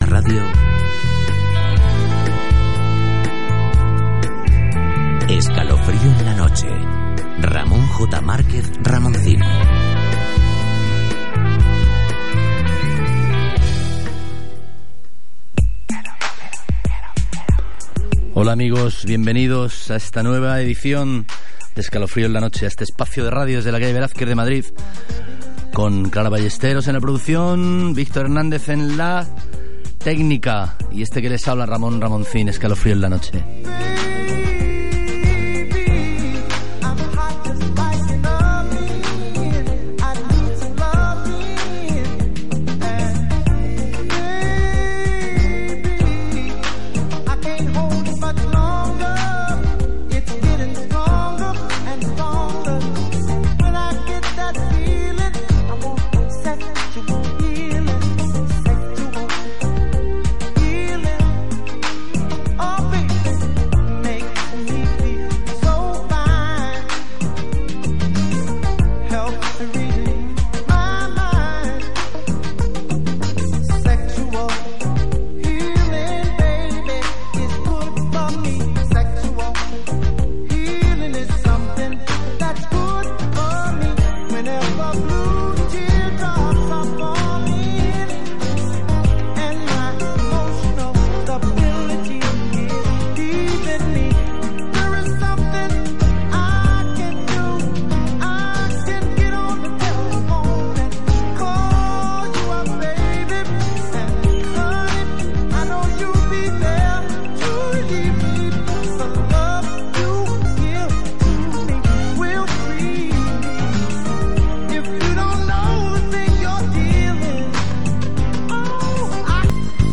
Ver reseñas y poemas de Los Tripulantes del Líricus recitados por Ramoncín en este enlace: